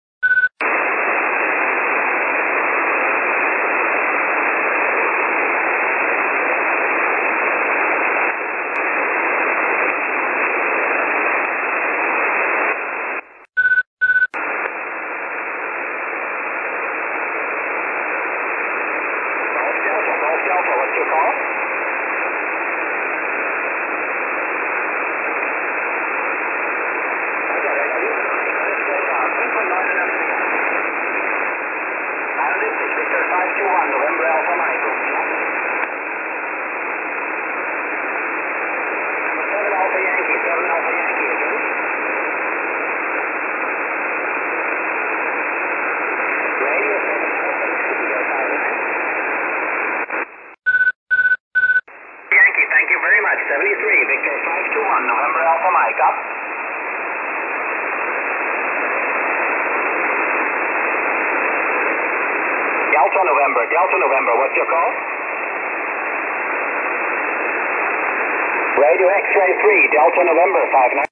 I’ve deliberately selected signals right on the limit, to show the capabilities of the aerials, after all, there’s little point in comparing strength 9 signals on the doorstep.
I have marked this with one ‘beep’ in the recordings.
I’ve marked the SRC X80 with two ‘beeps’ in the recordings.
I’ve marked this with three ‘beeps’ in the recordings.
First, the dipole (no copy), then the X80, finally the Steppir.